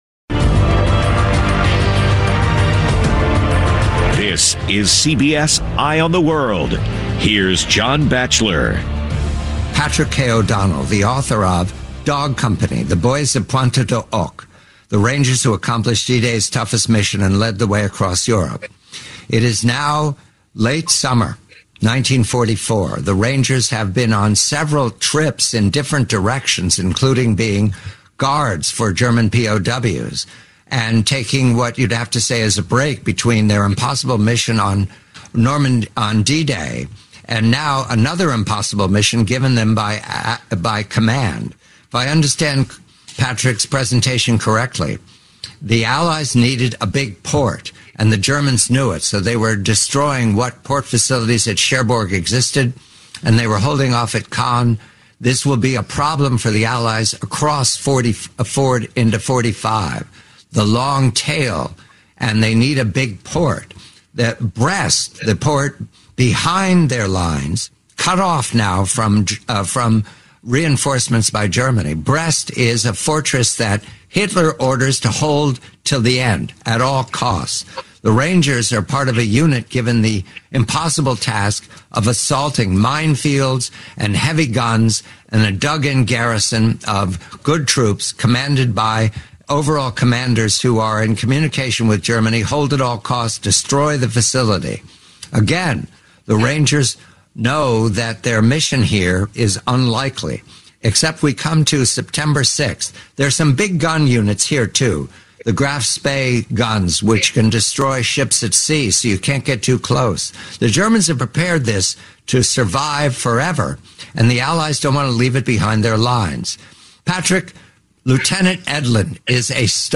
Blackstone Audio, Inc. Audible Audiobook – Unabridged